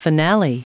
Prononciation du mot finale en anglais (fichier audio)
Prononciation du mot : finale